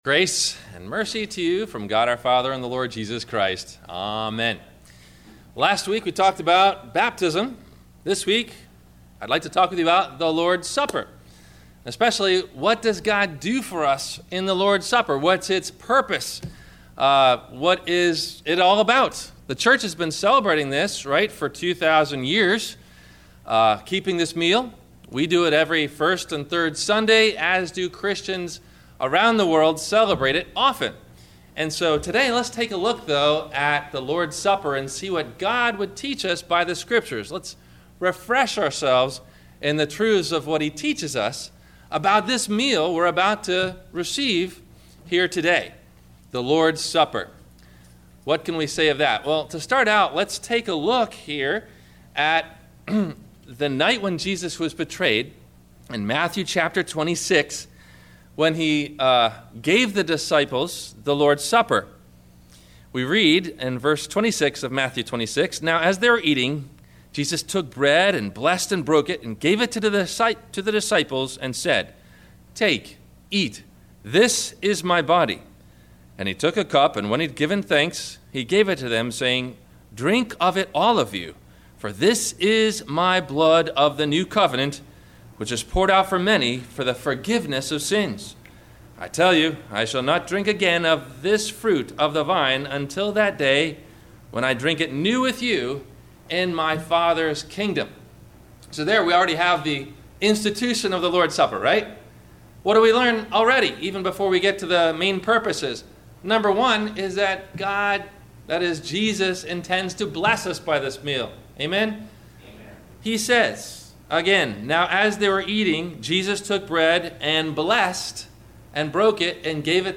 A Biblical Understanding of Baptism – Sermon – June 06 2010